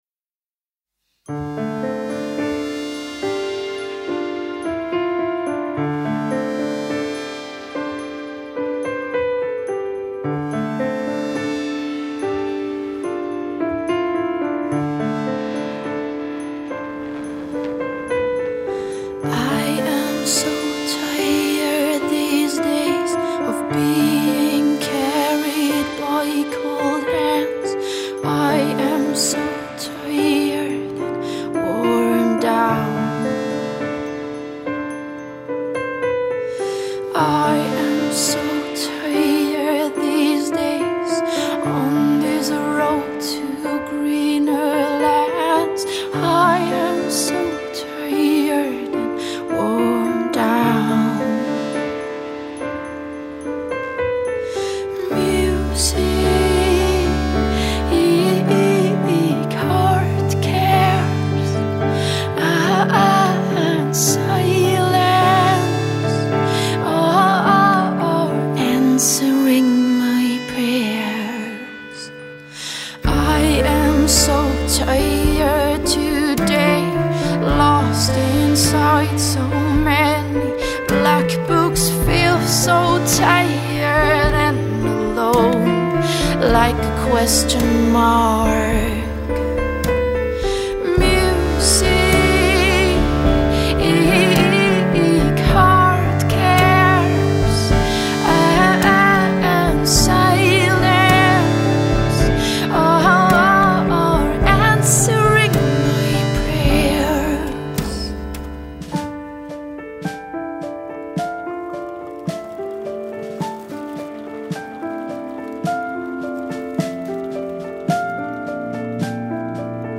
Ambient / Indie / Pop.